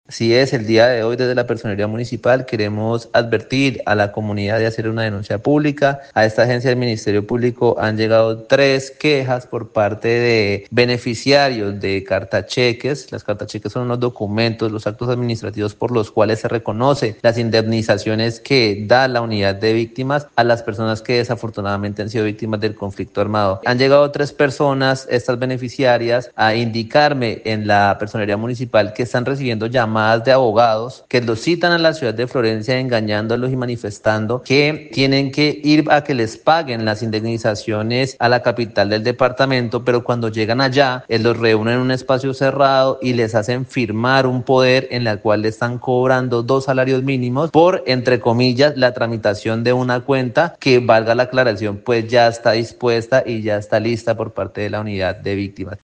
Camilo Lozada, Personero del municipio de San Vicente del Caguan, explicó que a su despacho han llegado al menos tres víctimas a poner en conocimiento que han sido timadas por lo que parece ser “falsos abogados”, por lo que investiga si puede existir complicidad desde la entidad en mención.